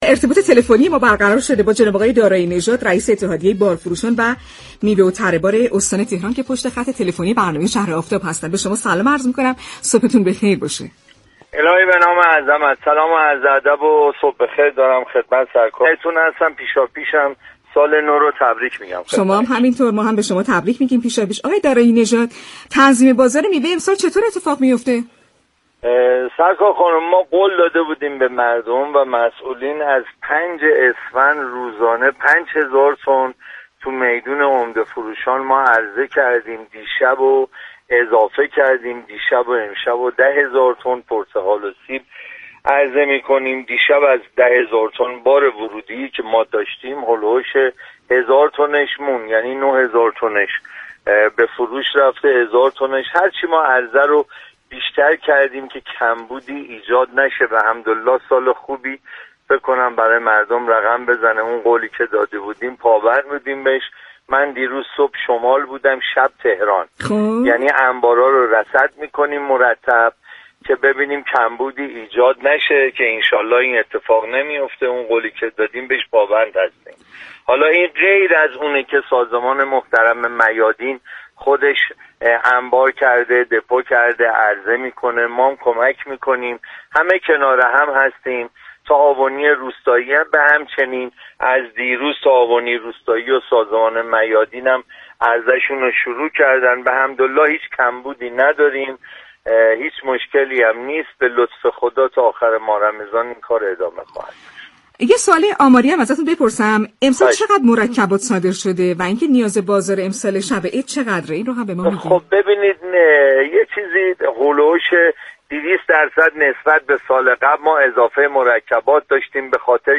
در گفت و گو با «شهر آفتاب»